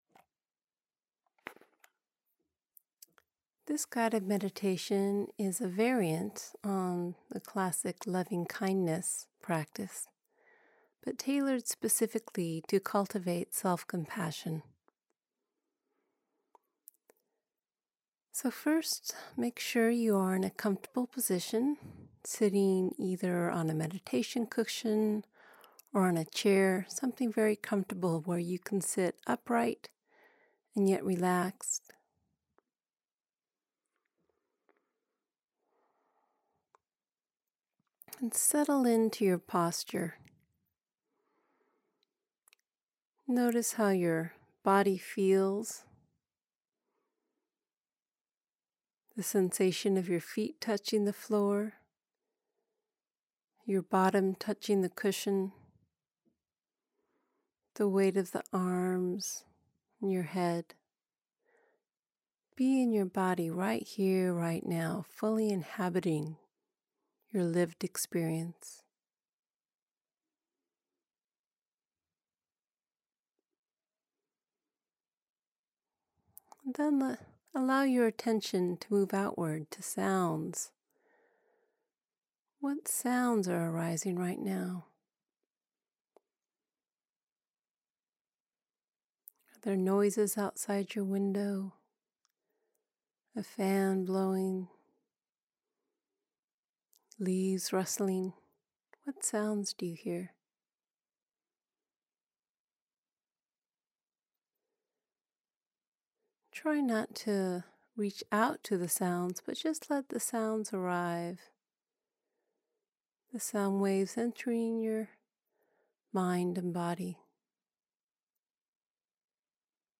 Self-compassion meditation video